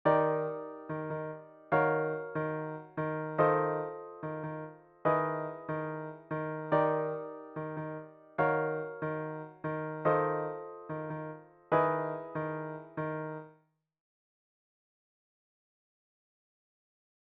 イントロ
イントロは8小節。ベースがE♭で固定のペダルトーンです